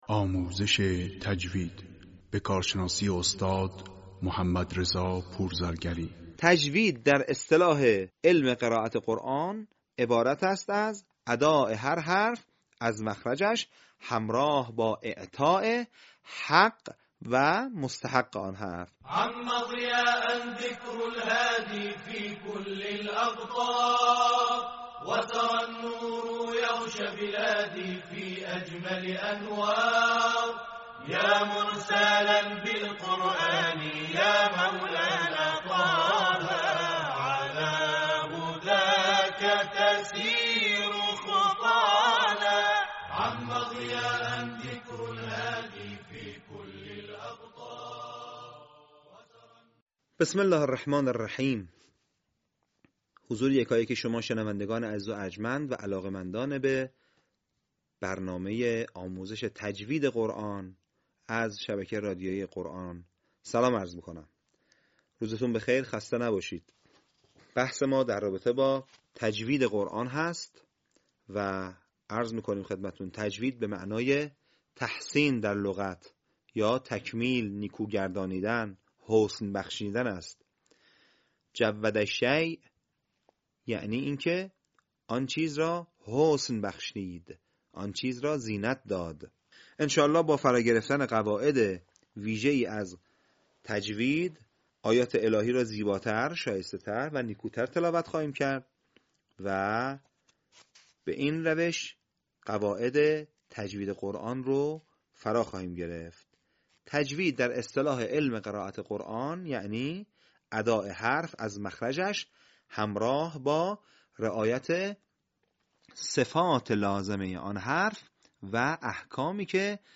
صوت | آموزش احکام «بسمله»